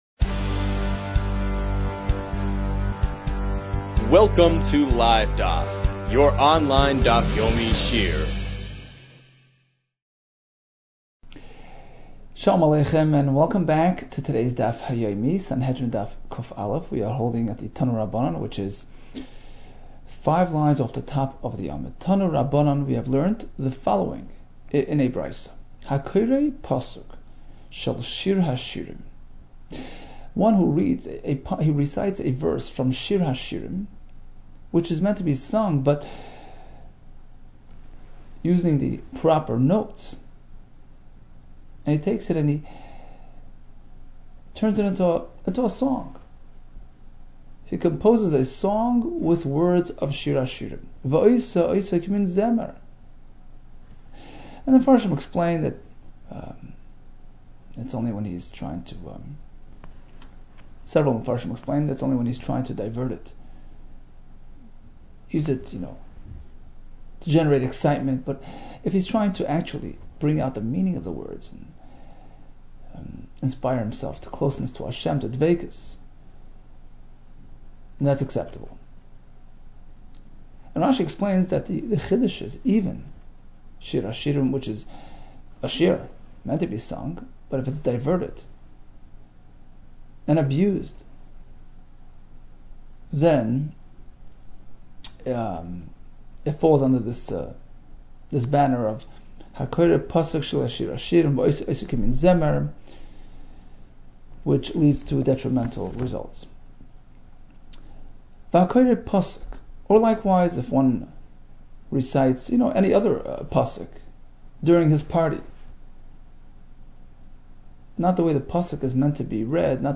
Sanhedrin 101 - סנהדרין קא | Daf Yomi Online Shiur | Livedaf